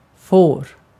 Ääntäminen
Synonyymit lamm Ääntäminen : IPA: [foːr] Haettu sana löytyi näillä lähdekielillä: ruotsi Käännös Ääninäyte Verbit 1. may US Substantiivit 2. sheep US UK 3. follower 4. idiot US Artikkeli: ett .